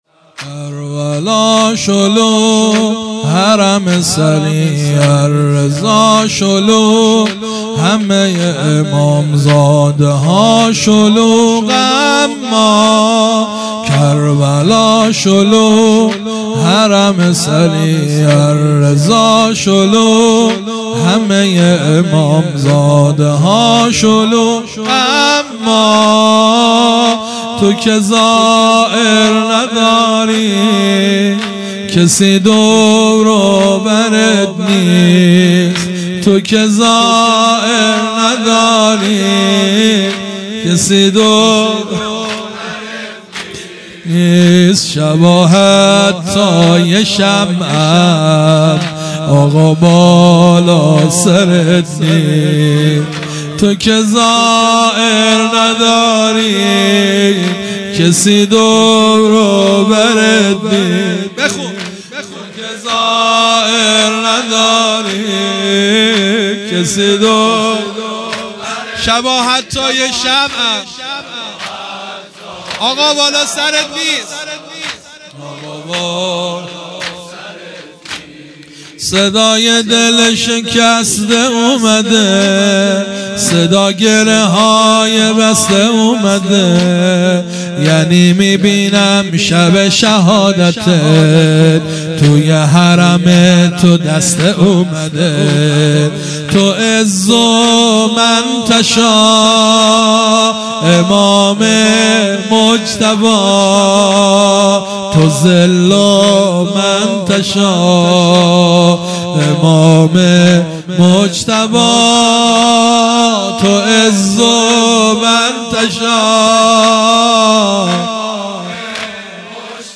شور |کربلا شلوغ حرم سریع الرضا شلوغ